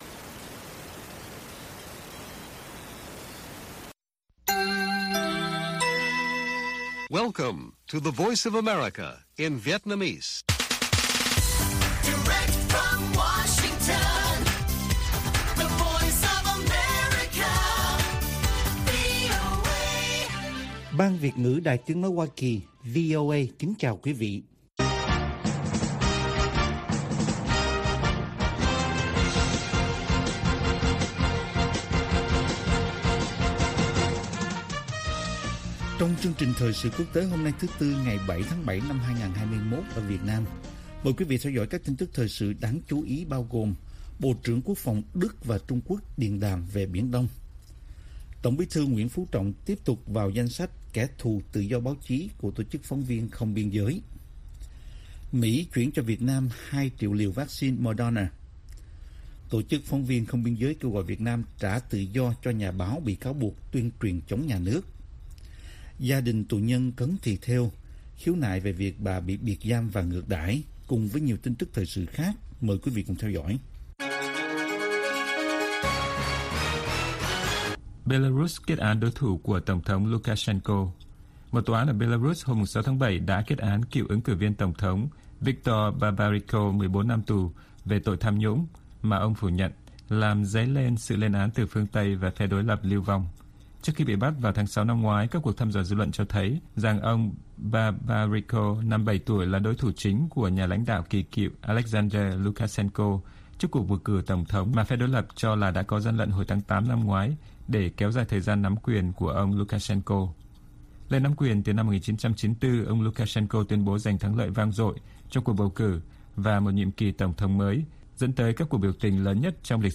Bản tin VOA ngày 7/7/2021